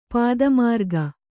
♪ pāda mārga